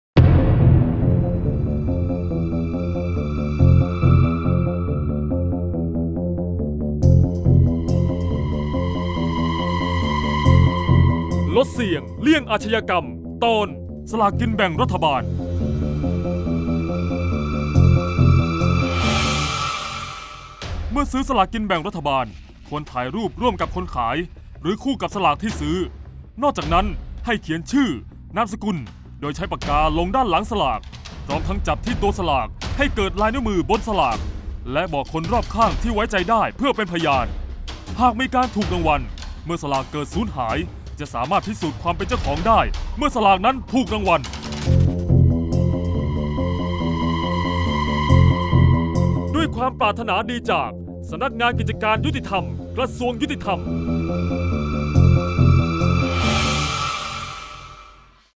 เสียงบรรยาย ลดเสี่ยงเลี่ยงอาชญากรรม 03-สลากกินแบ่งรัฐบาล